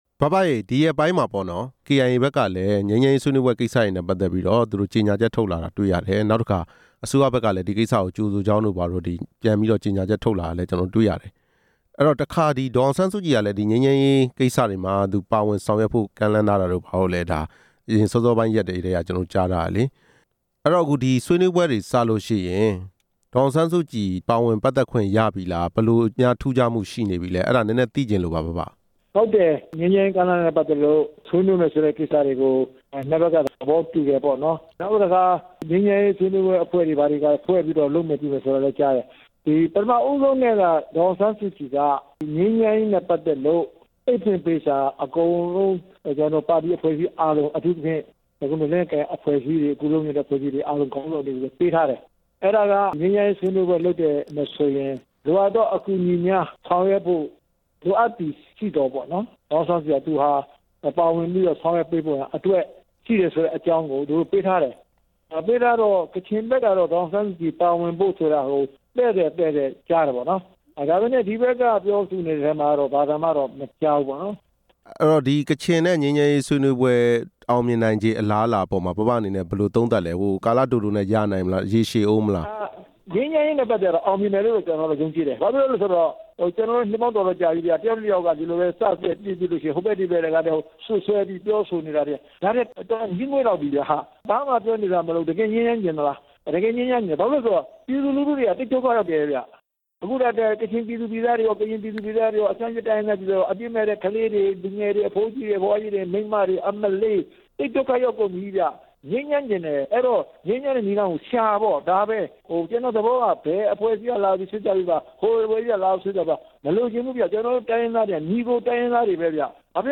တိုင်းရင်းသားအရေး အမျိုးသားဒီမိုကရေစီ အဖွဲ့ချုပ် နာယက ဦးတင်ဦးကို မေးမြန်းချက်